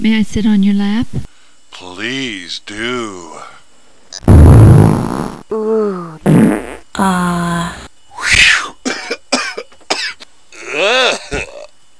Picture: A boy, a girl, 1 chair, & a bad case of gas.
lapfart.wav